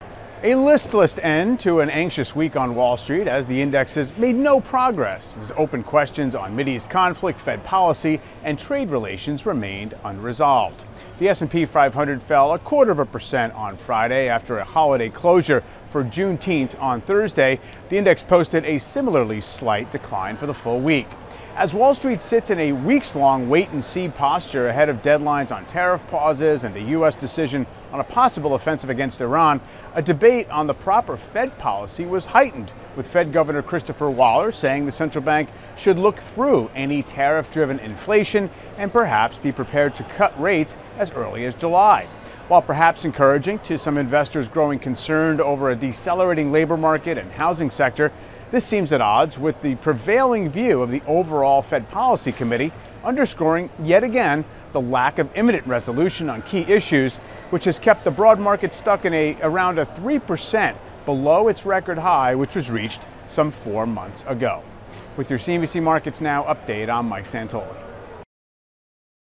CNBC Markets Now provides a look at the day's market moves with commentary and analysis from Michael Santoli, CNBC Senior Markets Commentator.